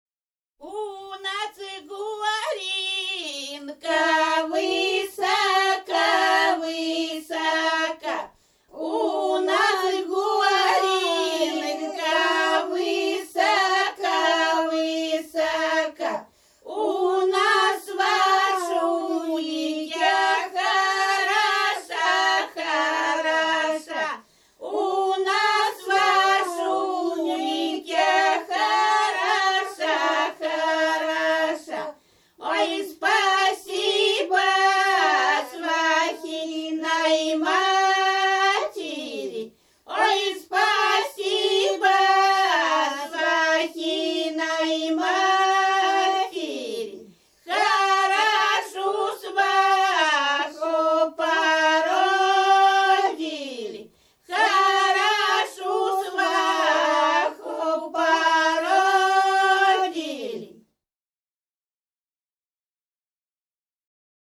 Рязань Кутуково «У нас горенка высока», свадебная.